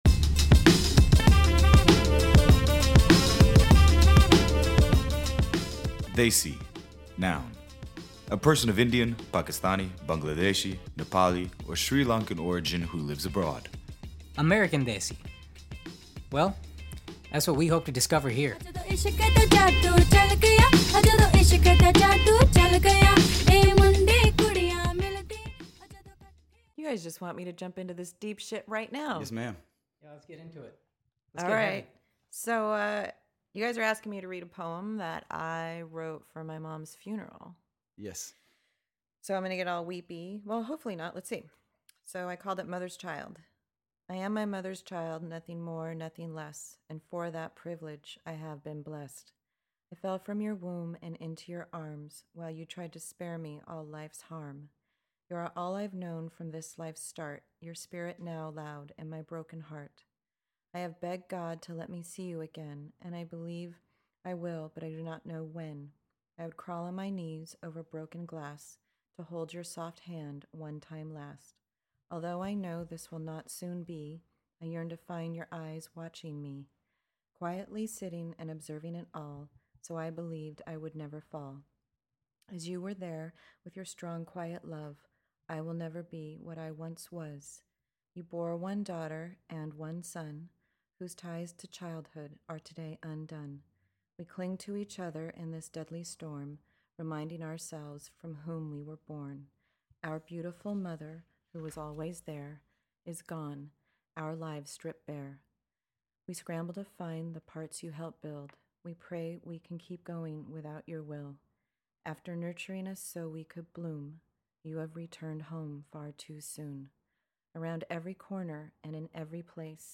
Within moments of starting the interview, we had launched into a discussion about the nature of vulnerability versus truth.